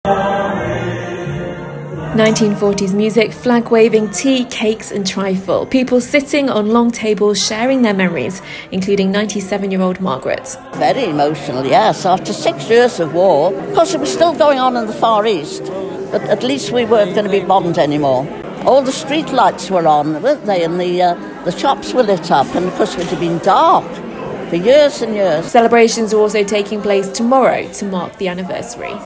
Street Party vibes at VE Day Celebrations in York
from our event